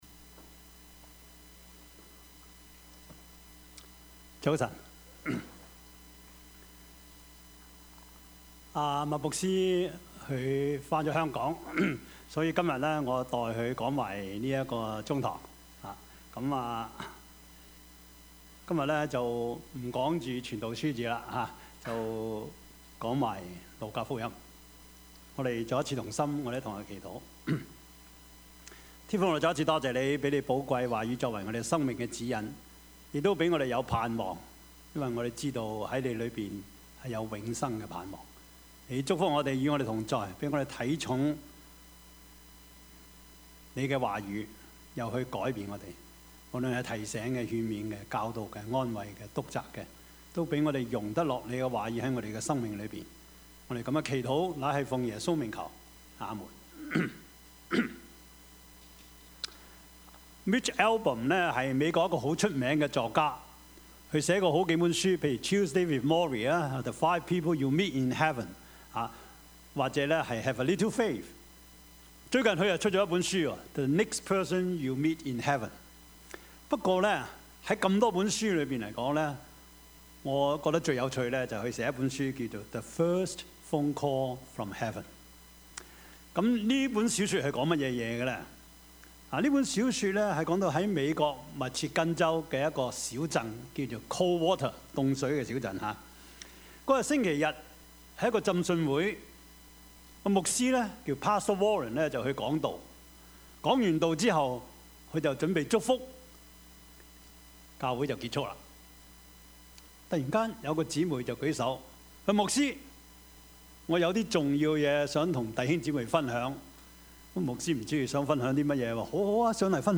Service Type: 主日崇拜
Topics: 主日證道 « 復活 – 真有其事?